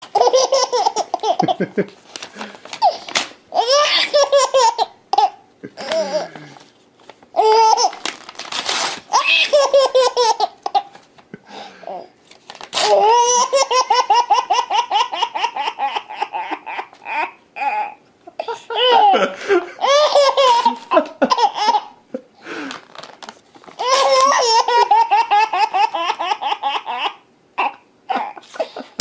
Kategorie Śmieszne